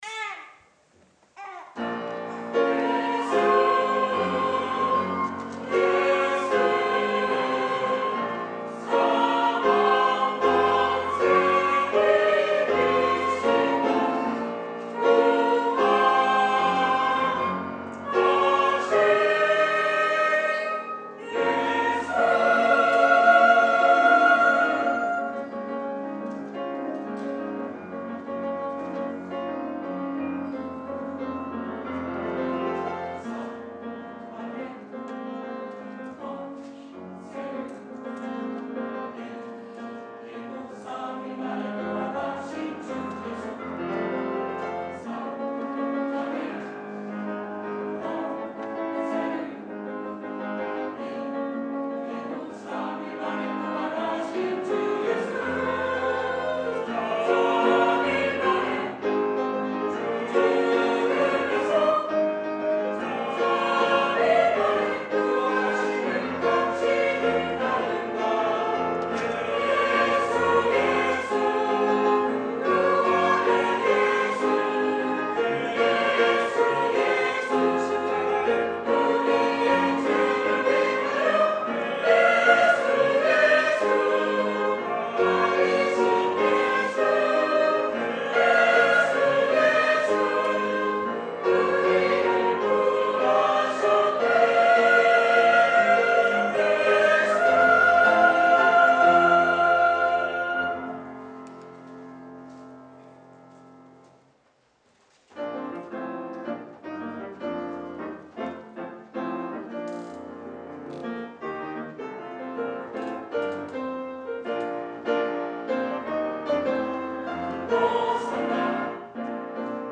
(4월16일) 부활절칸타타”예수”